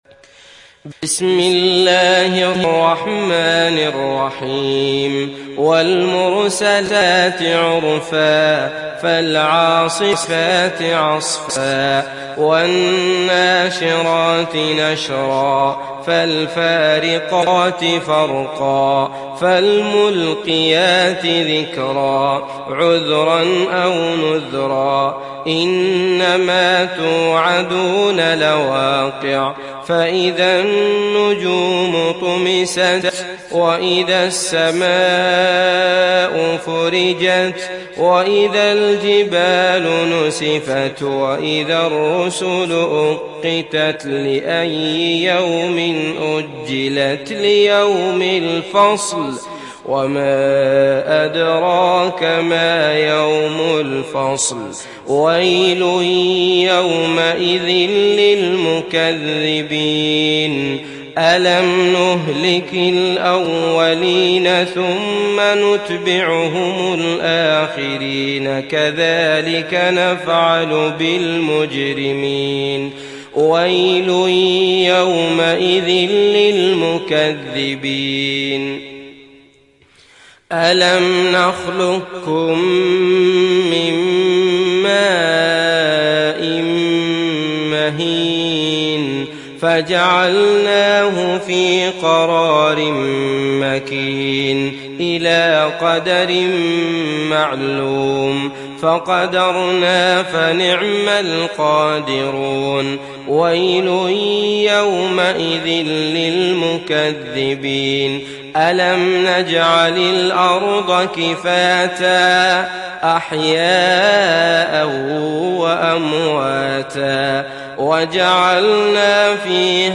تحميل سورة المرسلات mp3 بصوت عبد الله المطرود برواية حفص عن عاصم, تحميل استماع القرآن الكريم على الجوال mp3 كاملا بروابط مباشرة وسريعة